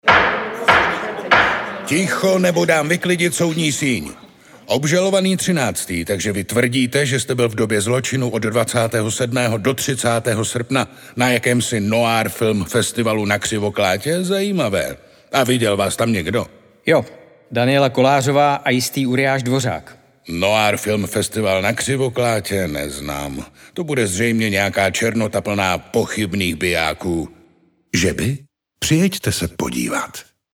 Představujeme vám letošní „noirovou audio pozvánku” na Radiu 1, kterou pro NFF namluvil jeho velký příznivec a letošní host Jiří Dvořák…